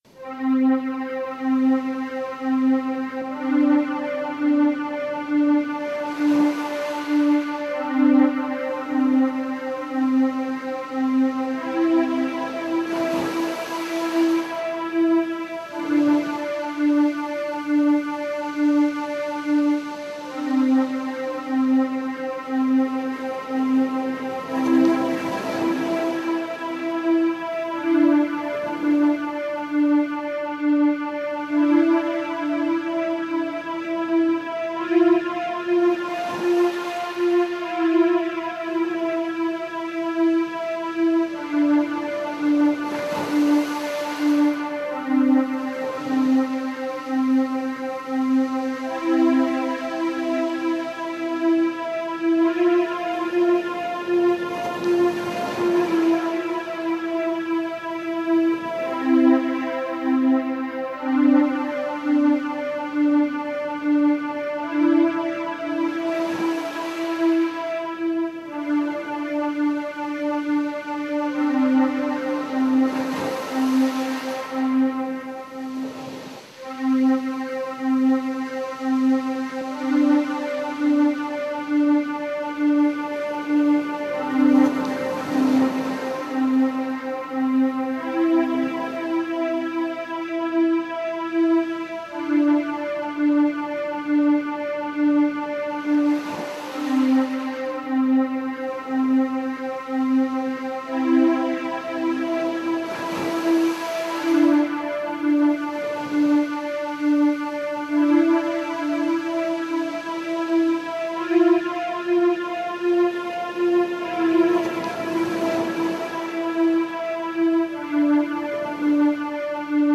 Lay Your Burden Down (Soaking, Instrumental)
Dieses instrumentale Soaking -Lied ist auch in manchen der christlichen Phantasiereisen als Hintergrundmusik enthalten.
Zum Video: Musik: * Keyboard ——————————- Wie wirkt die Aufnahme auf Dich?
lay-your-burden-down-soaking-instrumental.mp3